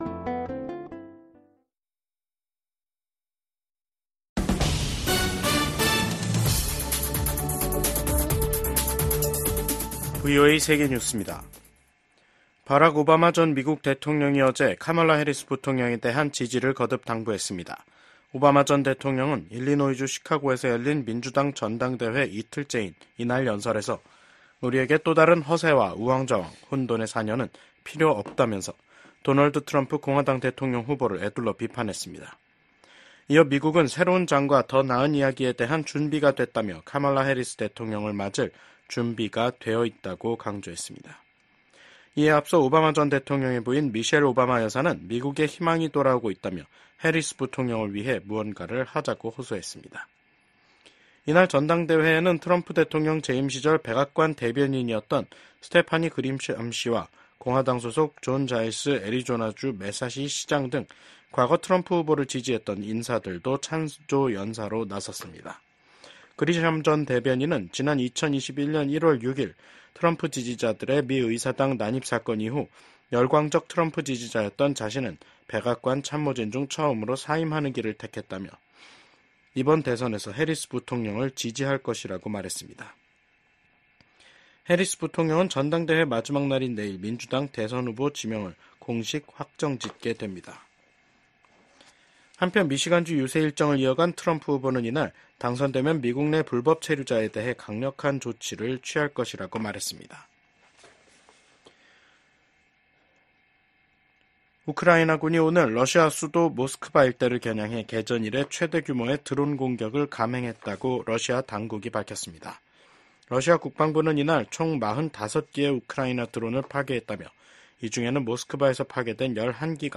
VOA 한국어 간판 뉴스 프로그램 '뉴스 투데이', 2024년 8월 21일 2부 방송입니다. 미국 국방부는 미한 연합훈련인 을지프리덤실드 연습이 방어적 성격이란 점을 분명히 하며 ‘침략 전쟁 연습’이란 북한의 주장을 일축했습니다. 주한 미 공군 전투기들이 23일까지 준비태세훈련을 실시합니다. 미국 정부가 윤석열 한국 대통령의 대북 접근 방식을 지지한다고 밝혔습니다.